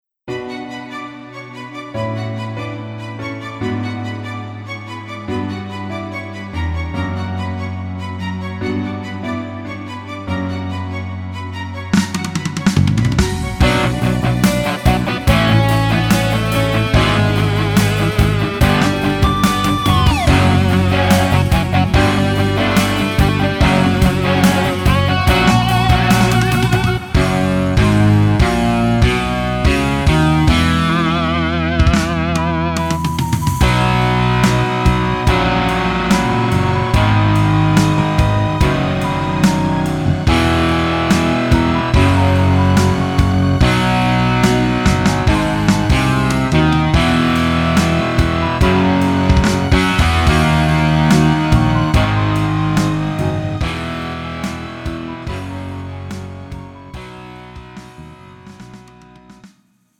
음정 원키 4:48
장르 가요 구분 Pro MR
Pro MR은 공연, 축가, 전문 커버 등에 적합한 고음질 반주입니다.